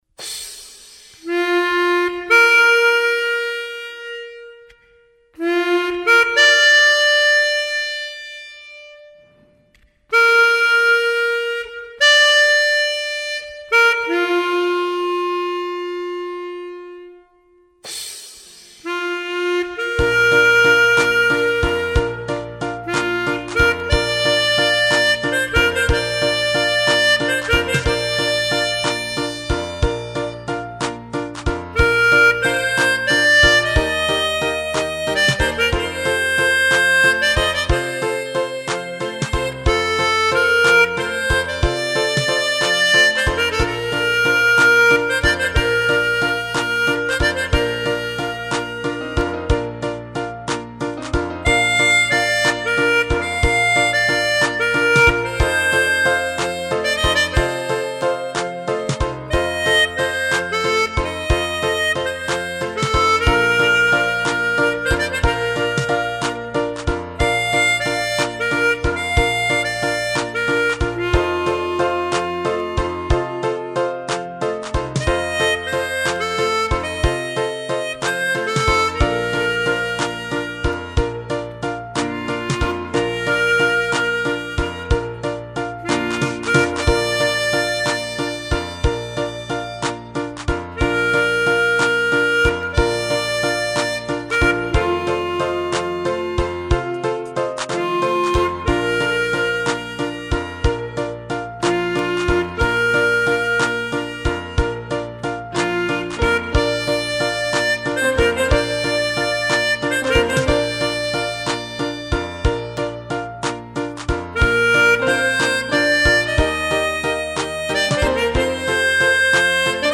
Je la joue en version d'origine c'est à dire en Sib.